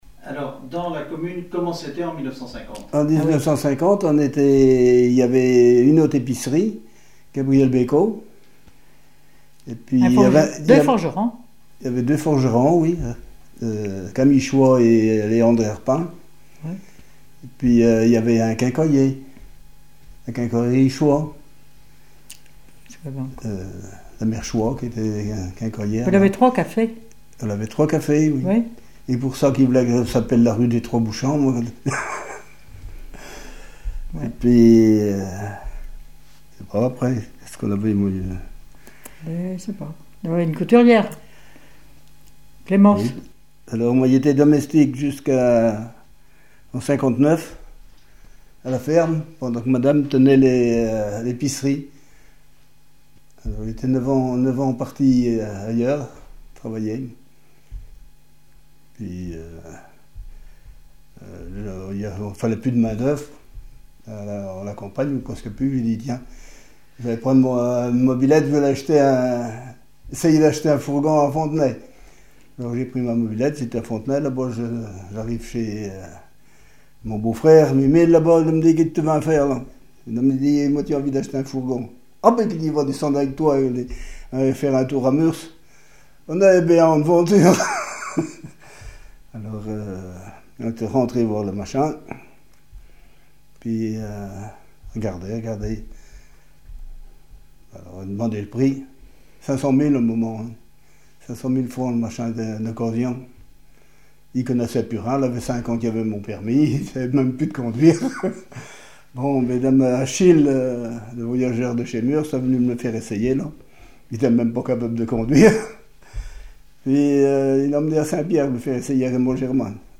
Témoignage sur un commerce
Catégorie Témoignage